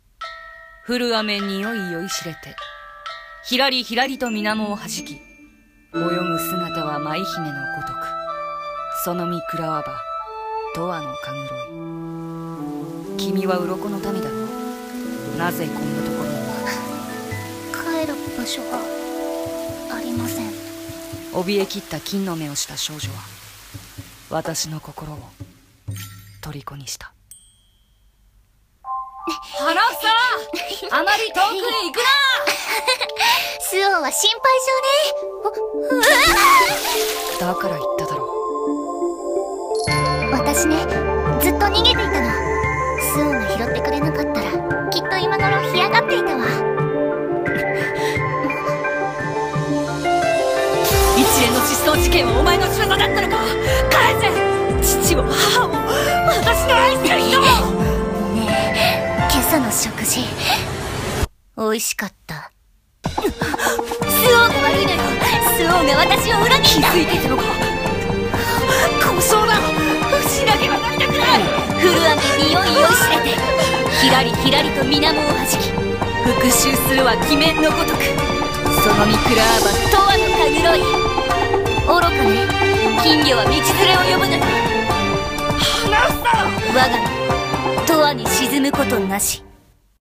CM風声劇「金魚の箱庭」